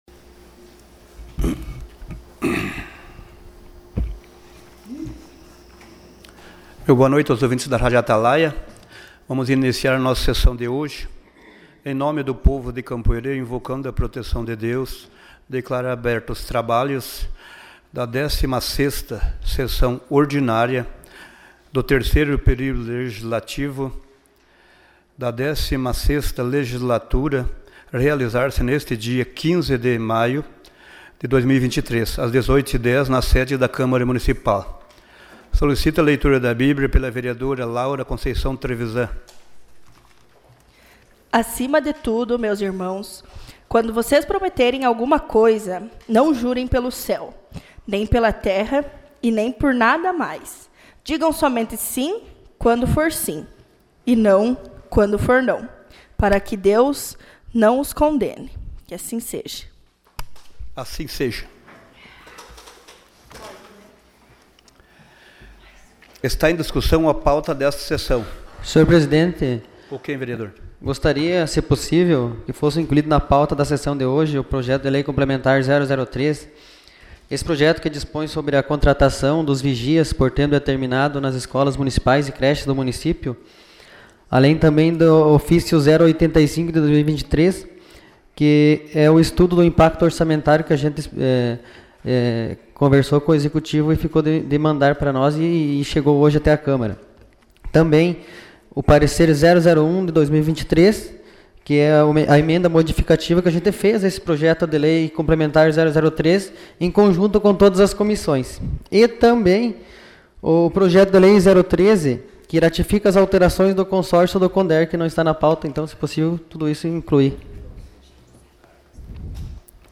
Sessão Ordinária dia 15 de maio